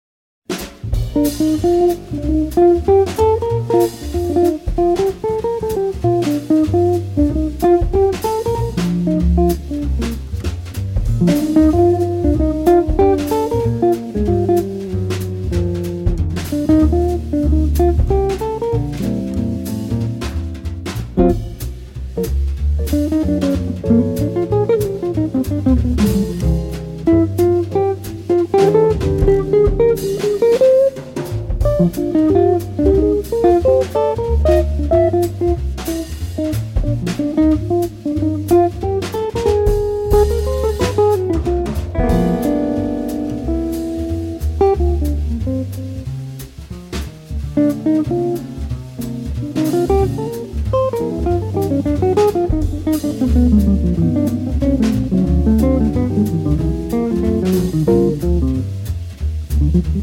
guitar.
drums
bass.